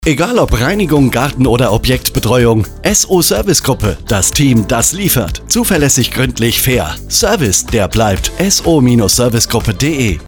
Radiospot :: SO-Servicegruppe
Radiospot Antenne Niedersachen.mp3